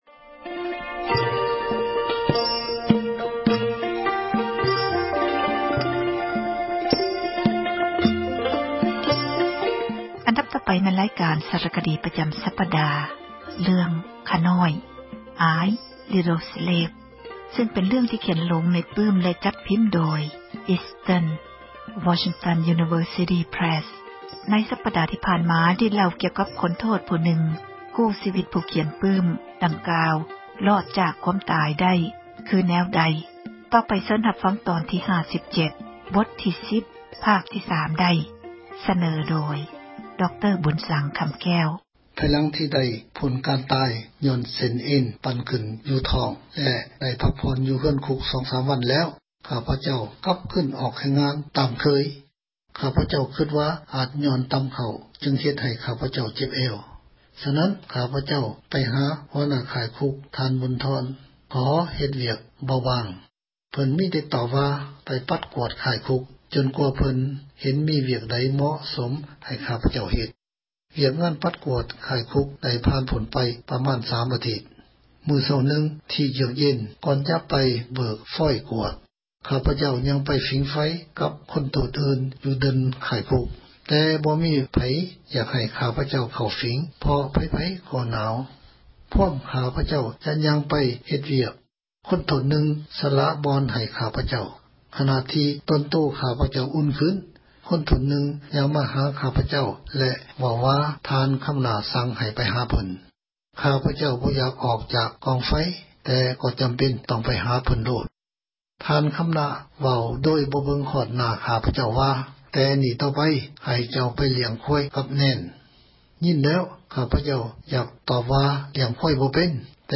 ສາຣະຄະດີ ເຣື່ອງ ”ຂ້ານ້ອຍ"